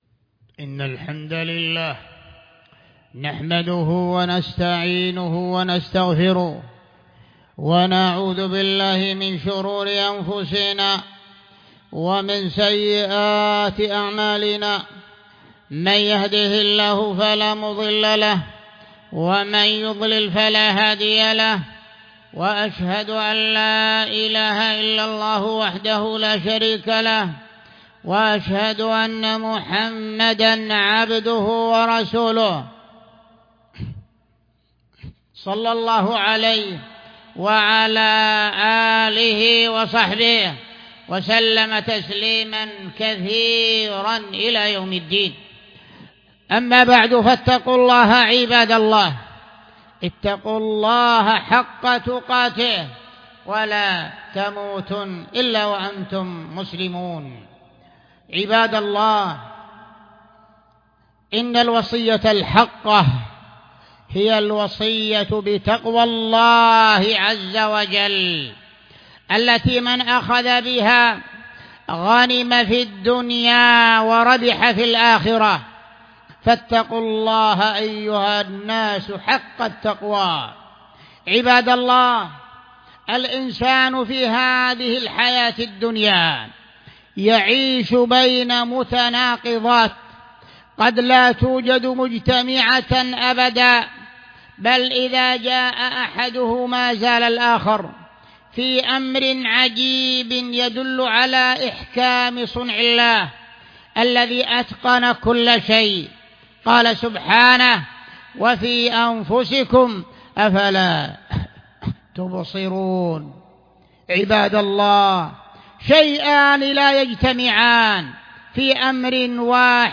14 فيفري 2020 م 4.9M 00:21:32 مواعظ ورقائق 406 111 باقي خطب الشيخ كل الخطب سماع الخطبة تحميل الخطبة شارك